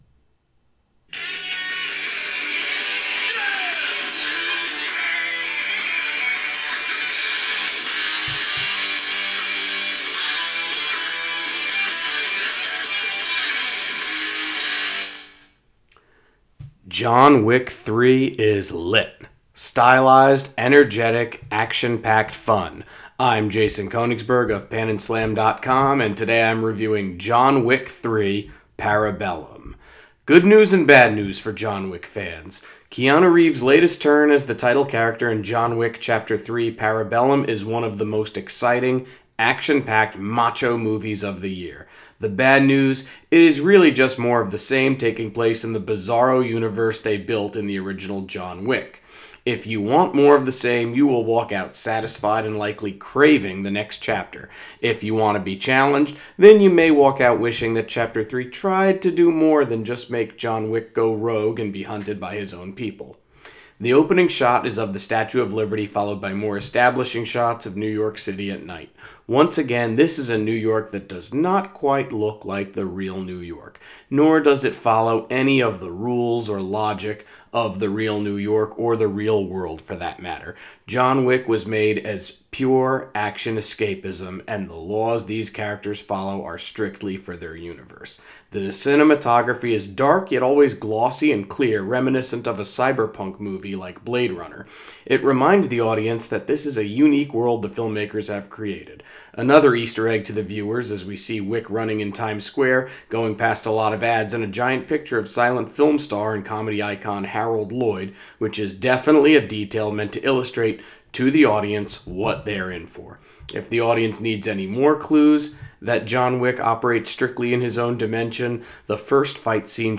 Movie Review: John Wick Chapter 3: Parabellum